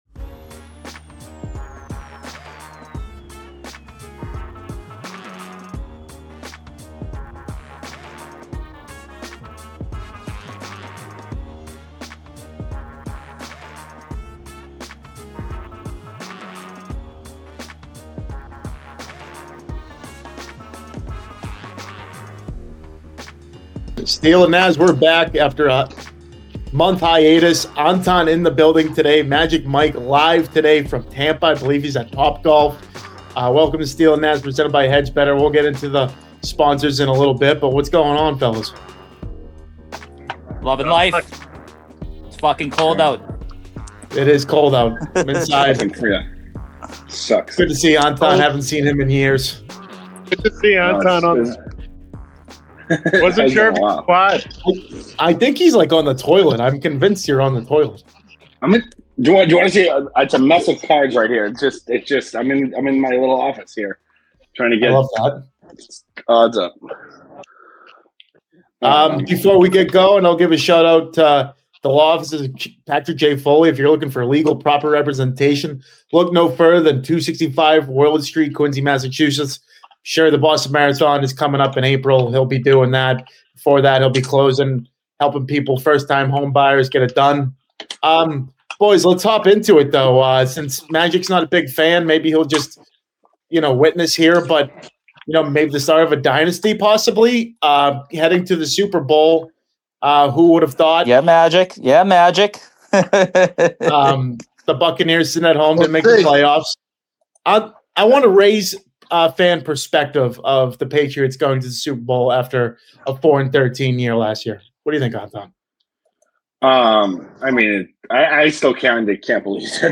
ran a no-guest REMOTE show, covering Super Bowl 60 and much more. - Patriots/Seahawks Super Bowl 60 Preview - Pats playoff recap